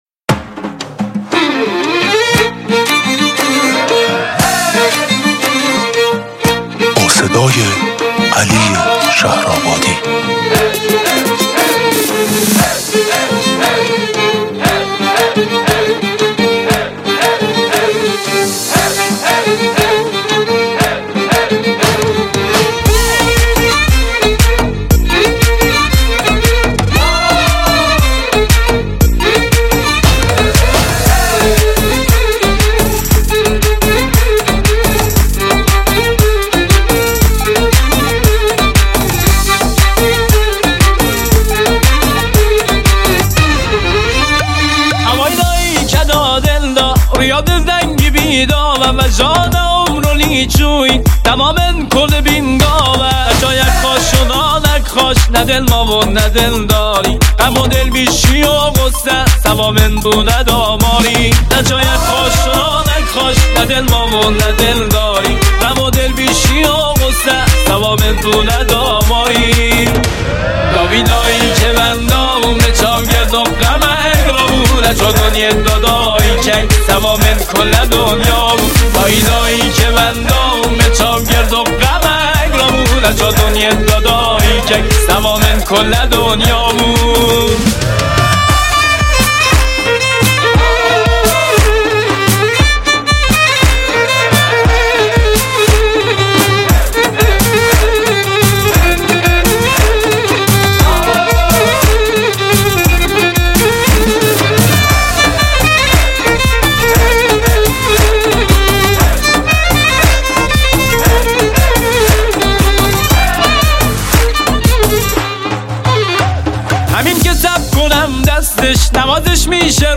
کرمانجی